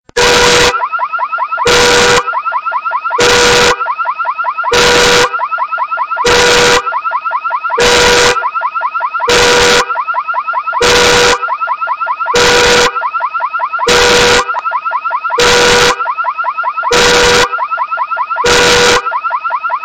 Звуки звонка, будильника
Будильник который очень раздражает